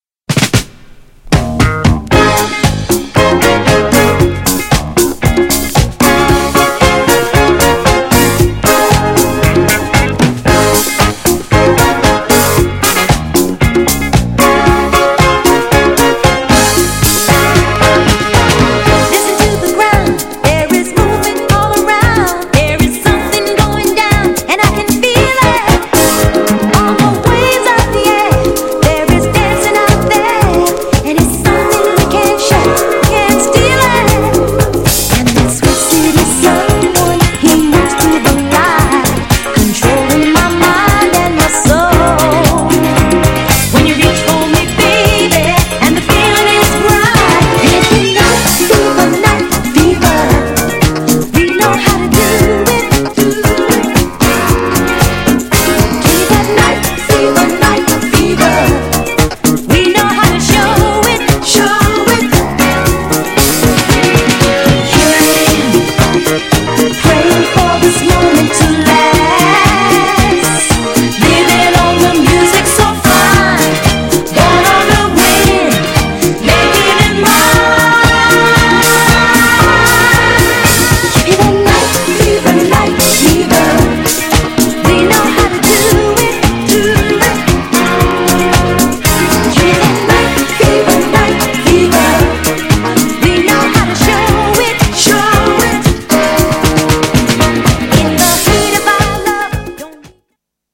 GENRE Dance Classic
BPM 121〜125BPM
HAPPY系サウンド
ストリングス # ハートウォーム # メロディアス